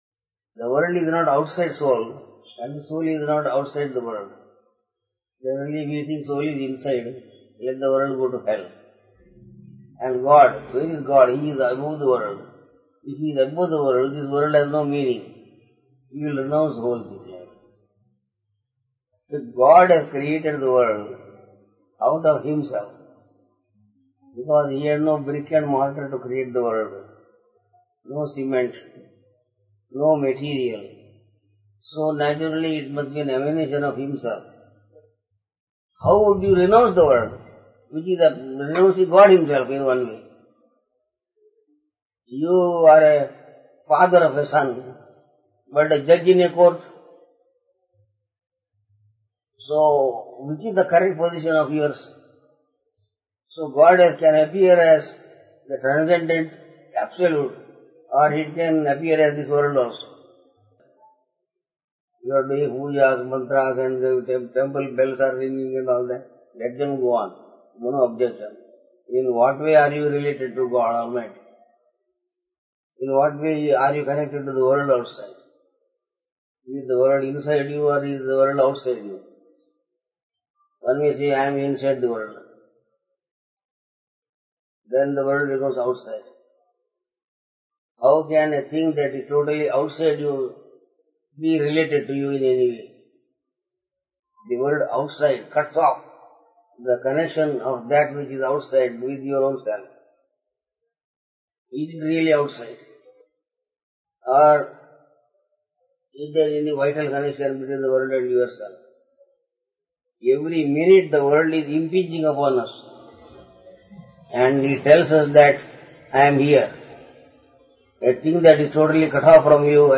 Darshan of Swami Krishnananda in 1999